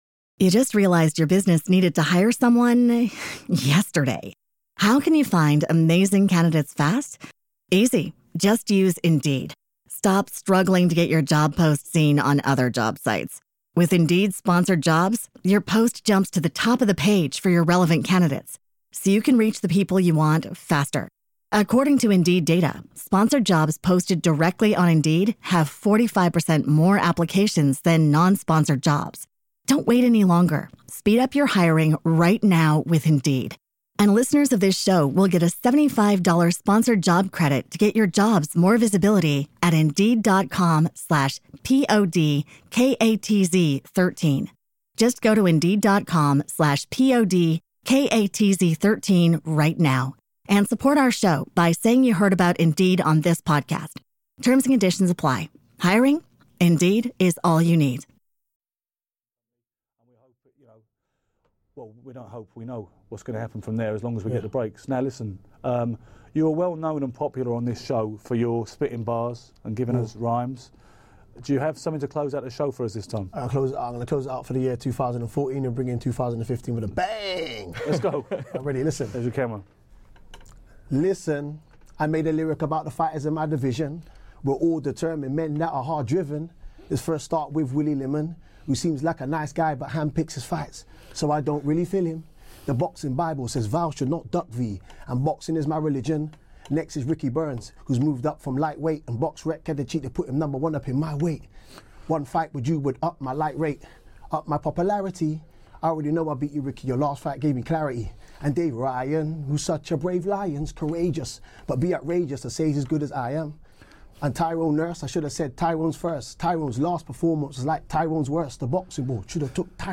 Today the guys in the studio are covering the Adonis Stevenson v Dmitriy Sukhotsky fight this Friday in Quebec.